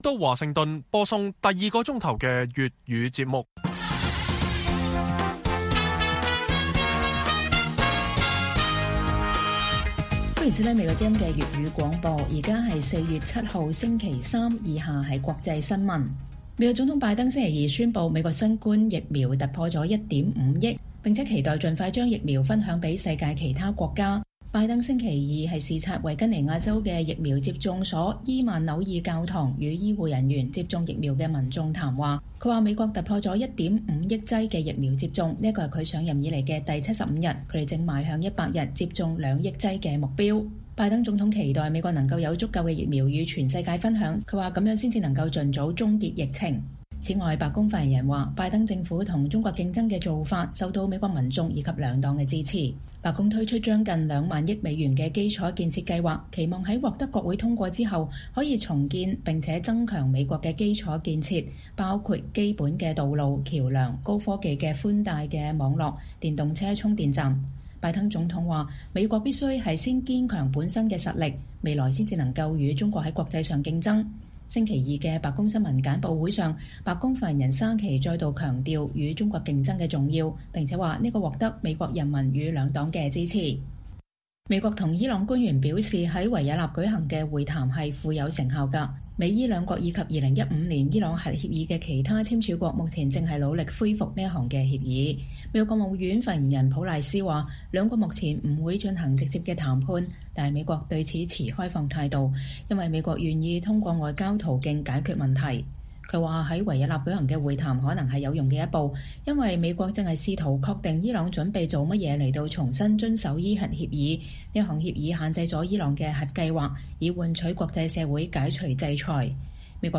粵語新聞 晚上10-11點